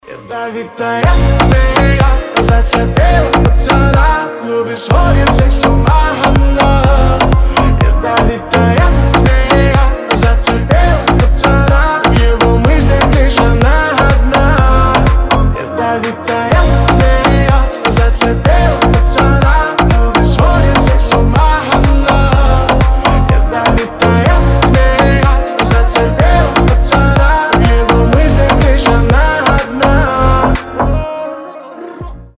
ритмичные